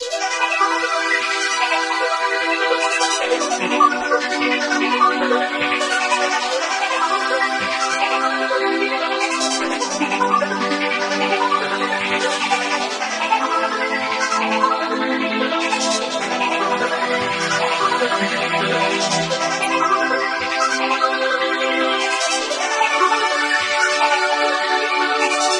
我喜欢他的文件中的声音和感觉，所以我精心设计了一下，想出了这个垫子和琶音。